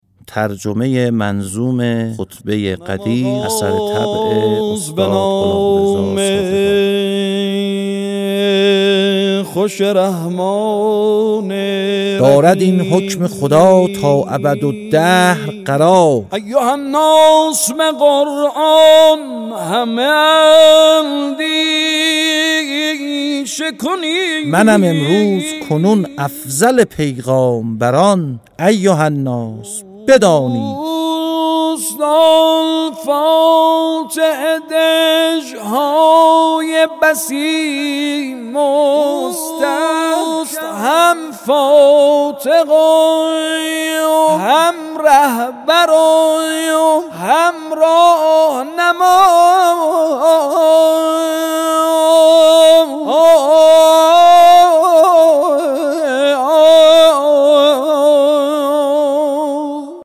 به روایت دکلمه و آواز